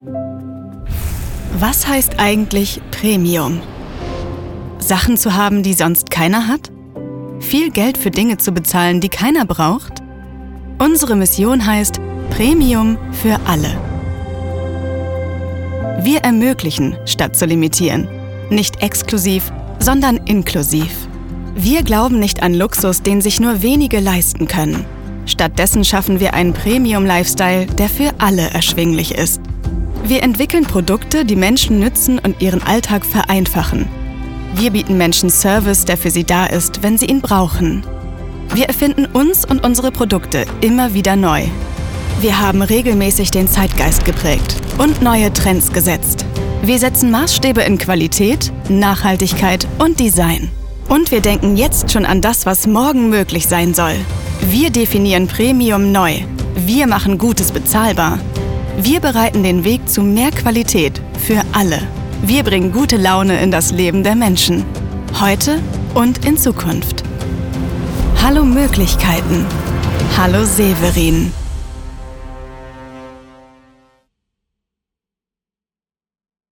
markant
Jung (18-30)
Norddeutsch
Narrative